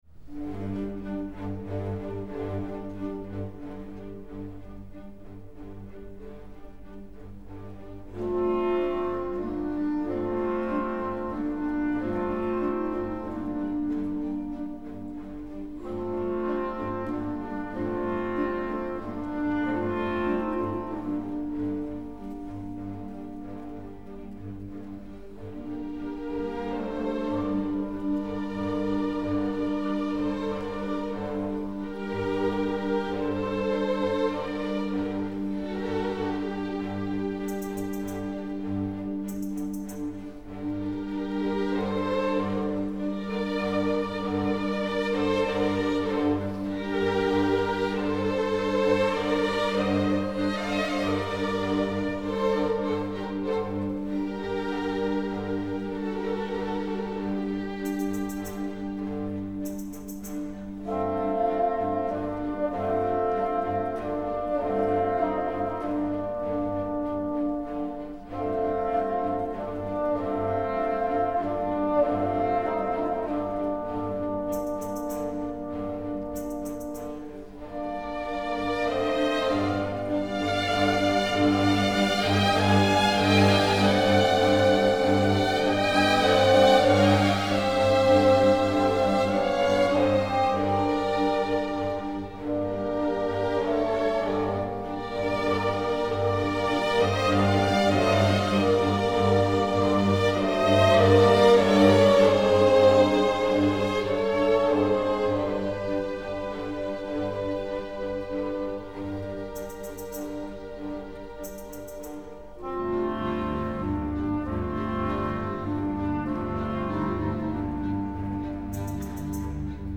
Ballet
Orquesta
Música clásica